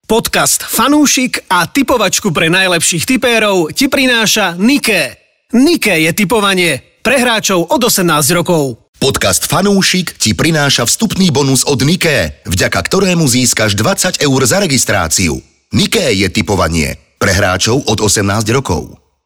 Daj si fanúšikovskú debatku o športe a tipovaní.